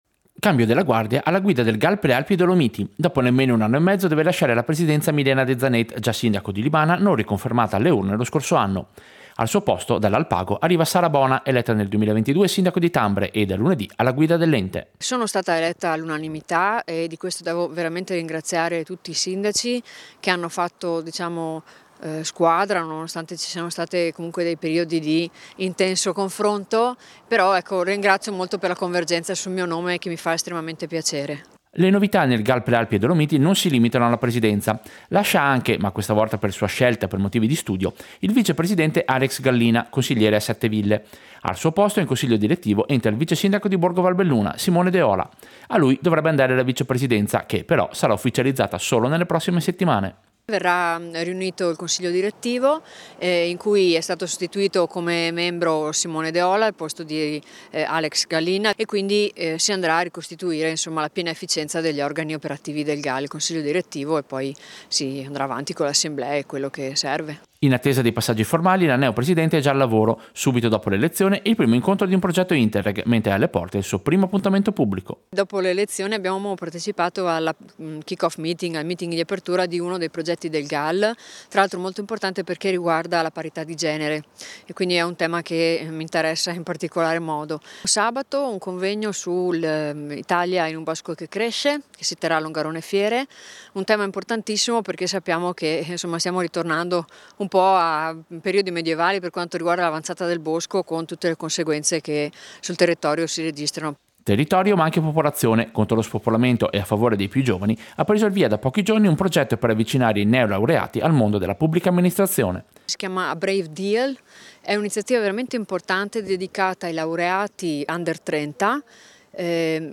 Servizio-Bona-presidente-GAL-Prealpi.mp3